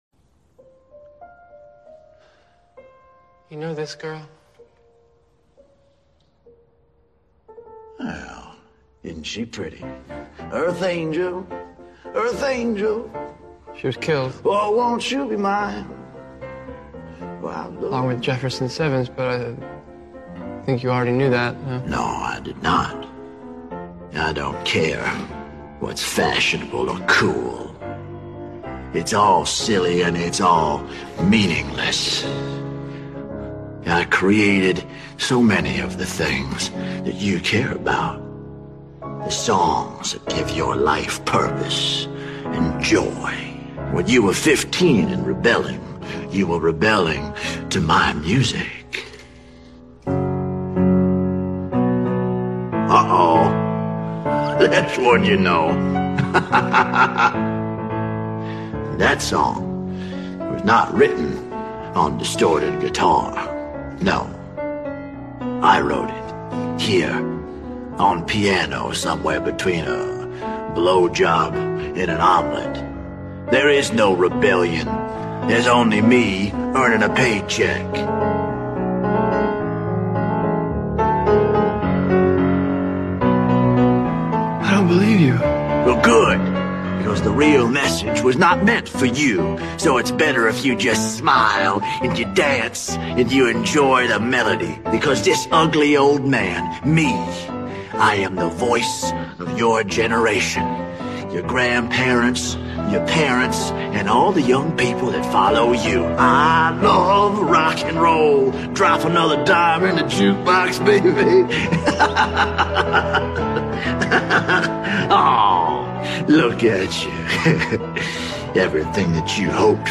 Project Hail Terry (Solo podcast)